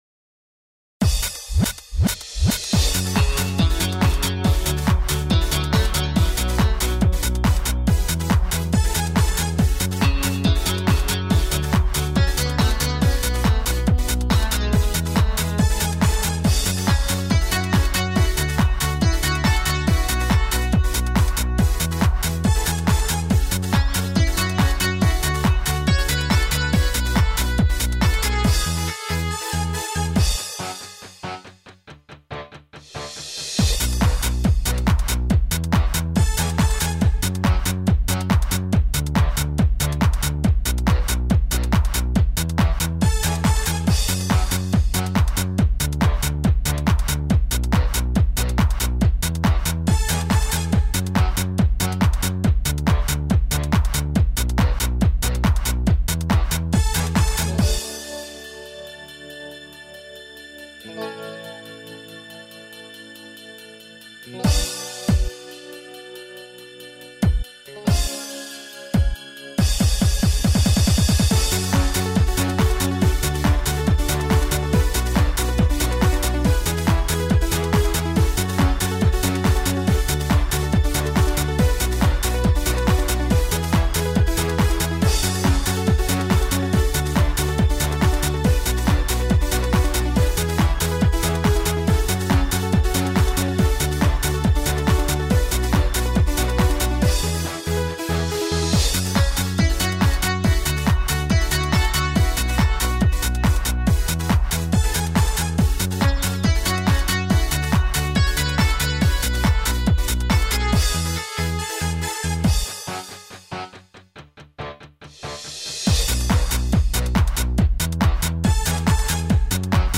Пойте караоке
минусовка версия 33160